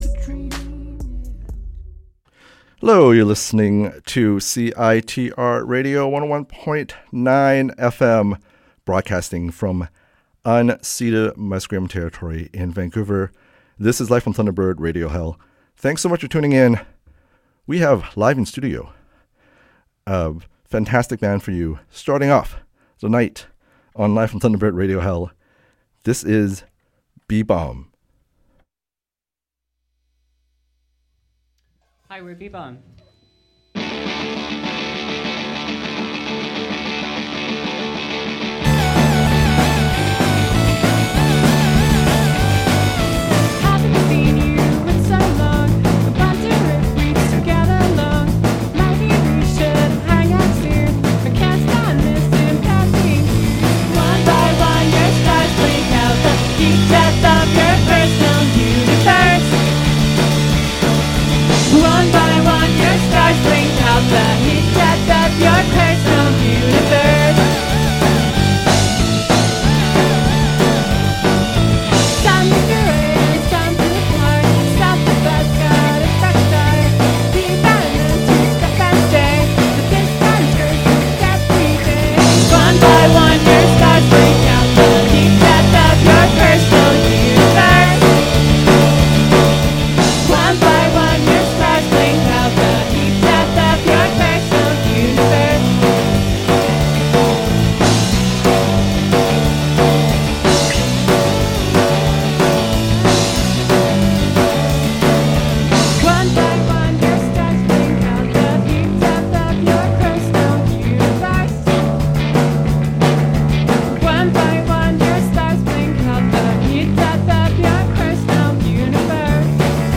Live in studio performance